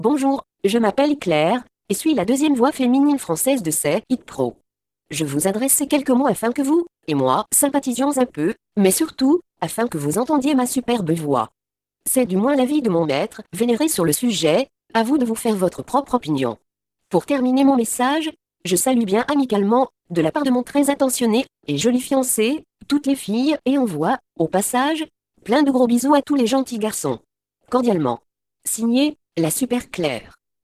Texte de démonstration lu par Claire, deuxième voix féminine française de LogiSys SayItPro (Version 1.70)
Écouter la démonstration de Claire, deuxième voix féminine française de LogiSys SayItPro (Version 1.70)